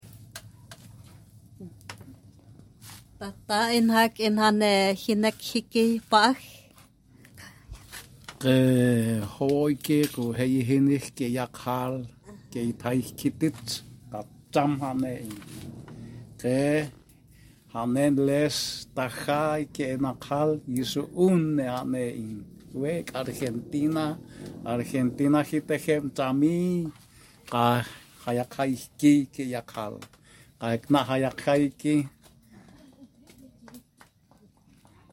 Zamuco Maria Auxiliadora, Carmelo Peralta, Alto Paraguay 2023 Mataguaya Maka Articulando e Construindo Saberes kuatiaa (Brasil) Serie del registro de la memoria oral En la comunidad de Ita Paso, Encarnaci ó n, Itap ú a se grabaron relatos en la lengua mak á (mataguaya) desde 2022 para fines de revitalizaci ó n y did á cticos.